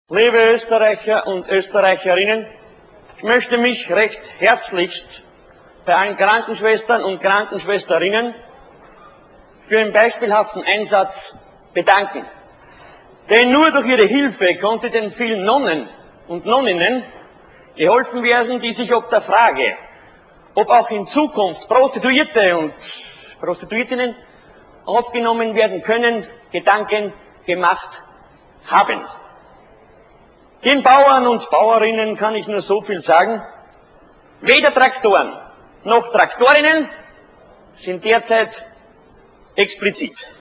Innen - Conference - 0:40 (52 KB)